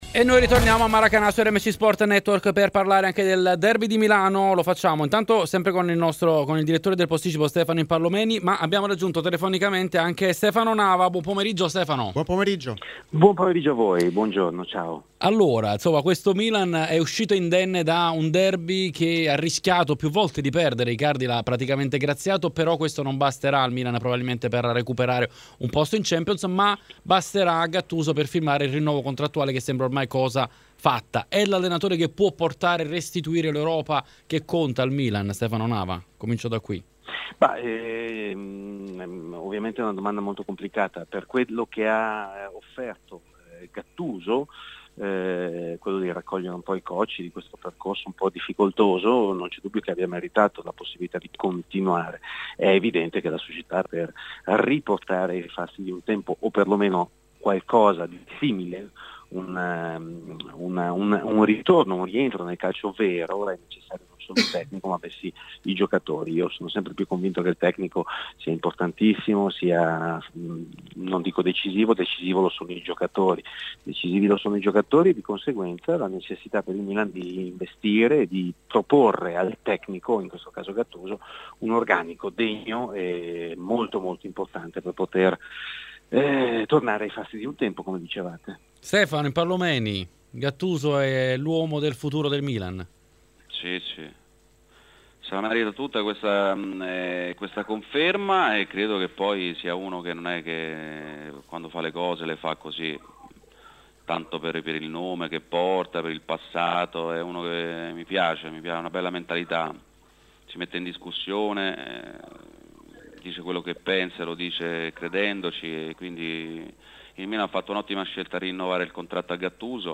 intervistati